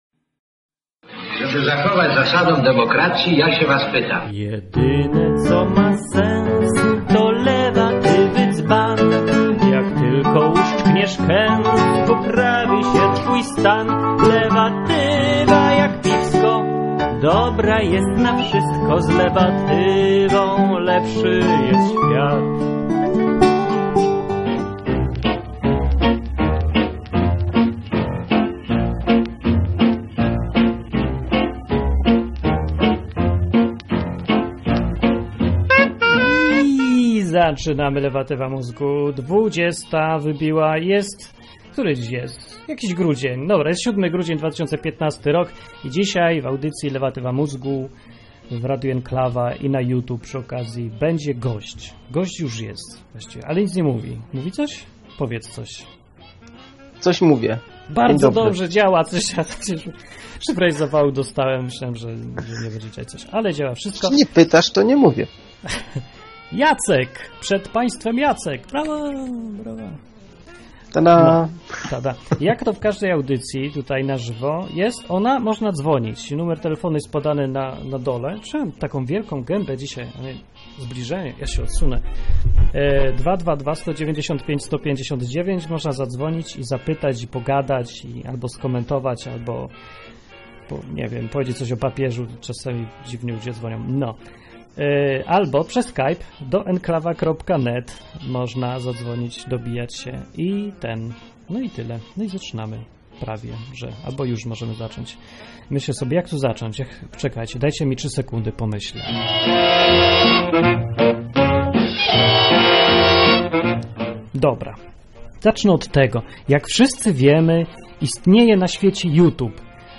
Wywiad z Einsteinem
Program satyryczny, rozrywkowy i edukacyjny.